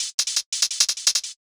UHH_ElectroHatA_170-03.wav